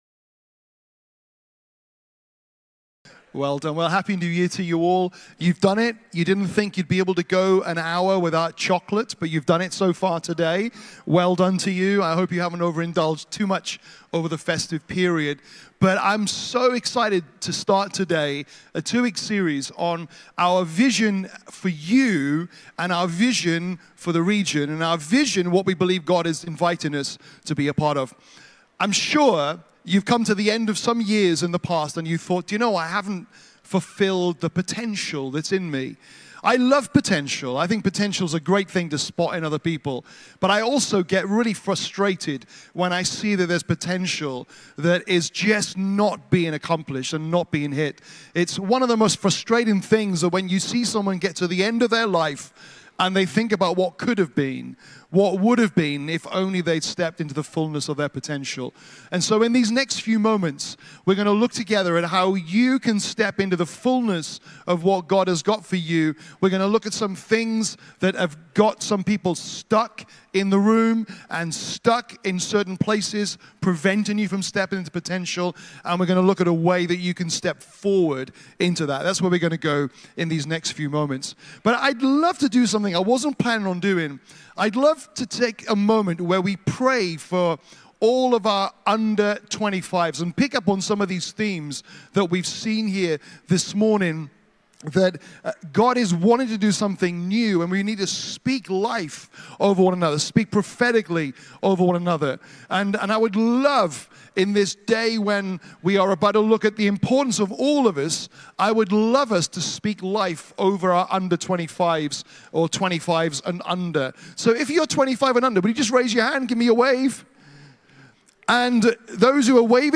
Rediscover Church Exeter | Sunday Messages Are You Aligned With God's Purpose?